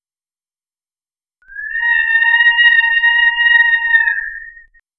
Tennesse_scream.wav